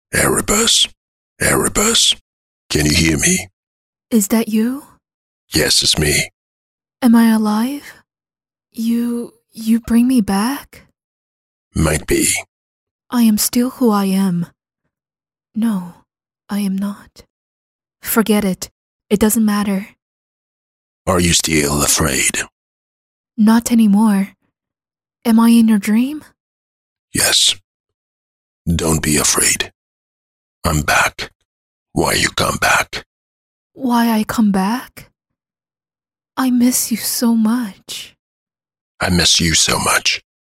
角色对话